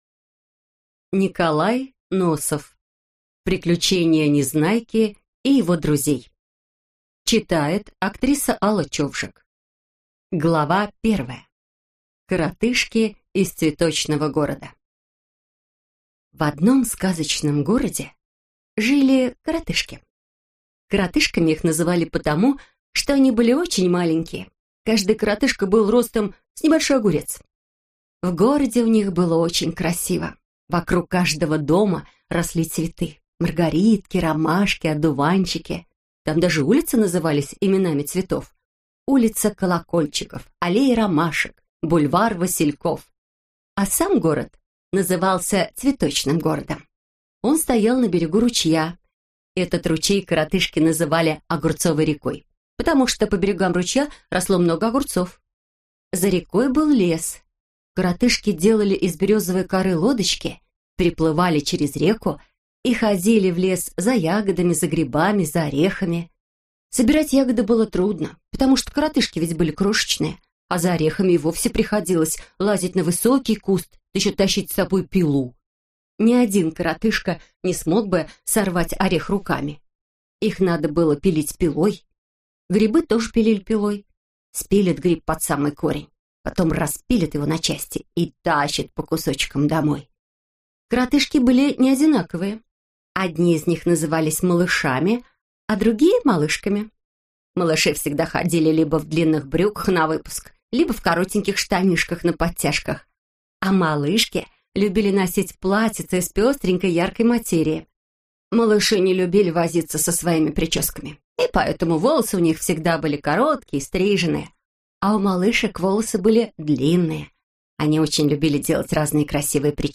Аудиокнига Приключения Незнайки и его друзей | Библиотека аудиокниг